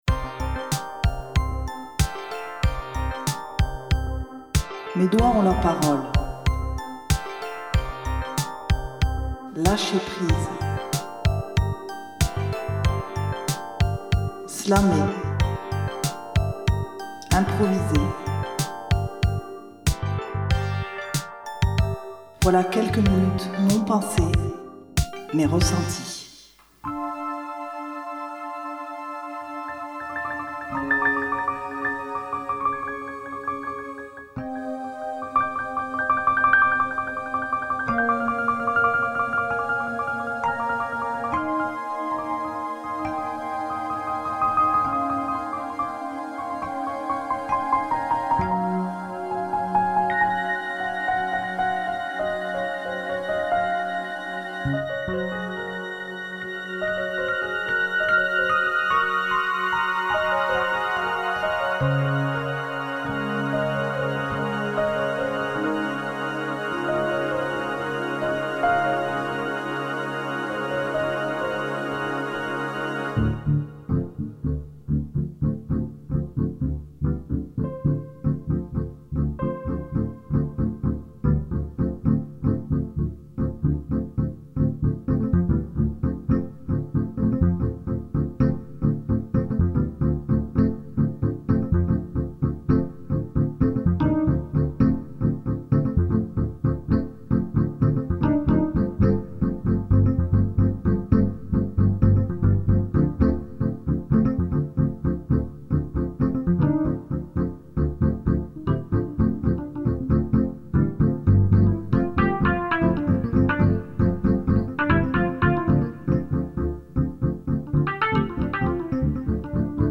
7e épisode d'impro